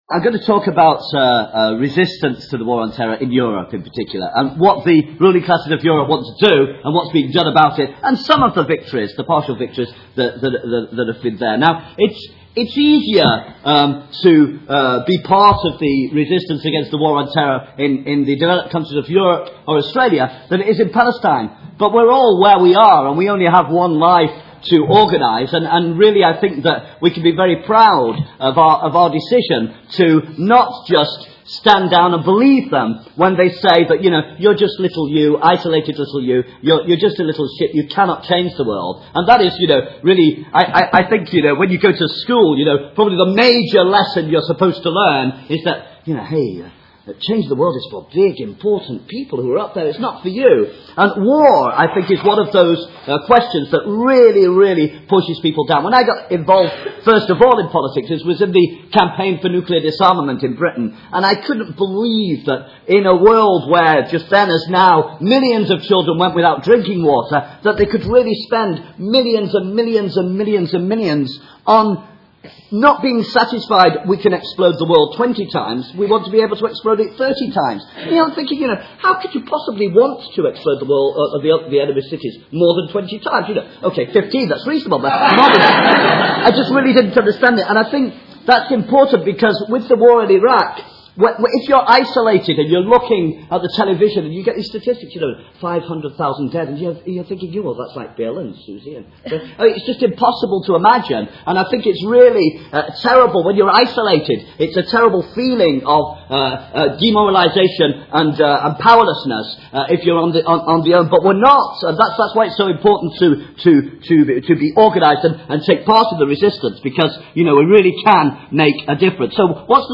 Marxism 2007 Play talk